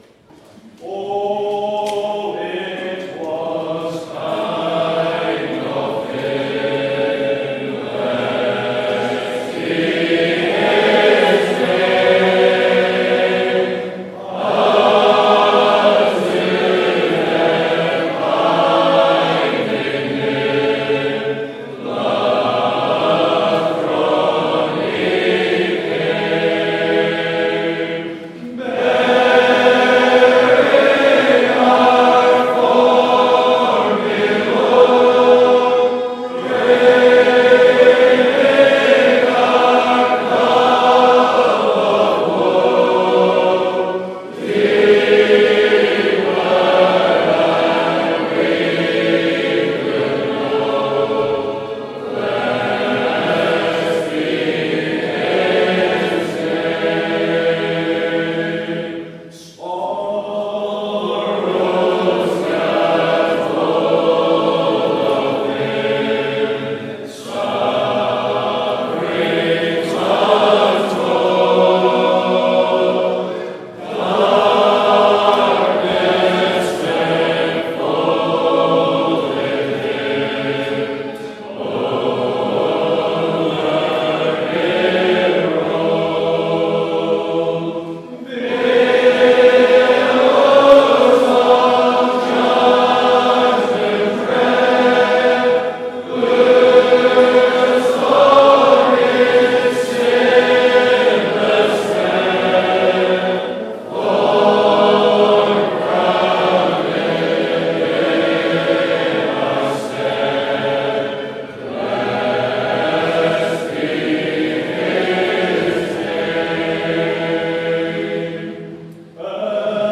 2026 Easter Conference Hymn Singing 3/3 (48 mins)
Hymn Singing from the “Monday Night Hymn Sing” of the Easter Weekend.
2026-Easter-Hymn-Singing-Part-3.mp3